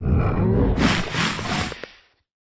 Minecraft Version Minecraft Version latest Latest Release | Latest Snapshot latest / assets / minecraft / sounds / mob / warden / sniff_3.ogg Compare With Compare With Latest Release | Latest Snapshot
sniff_3.ogg